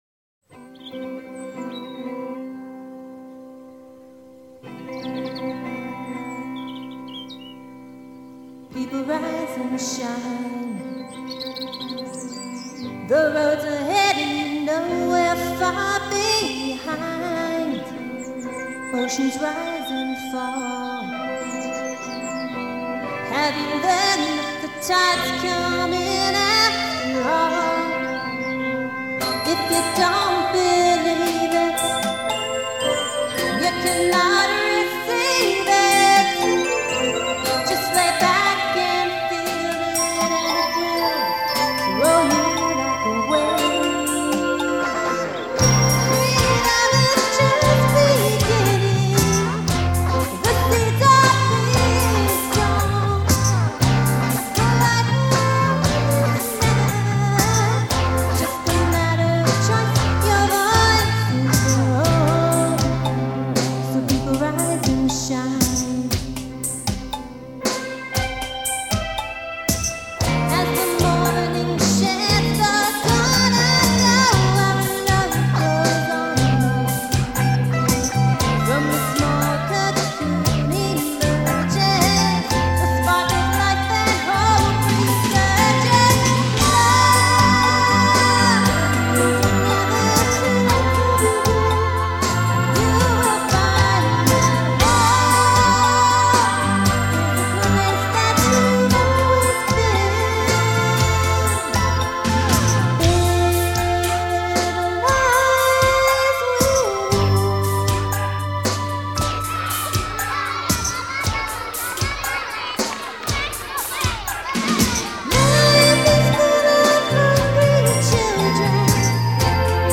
Practice Session - circa 1976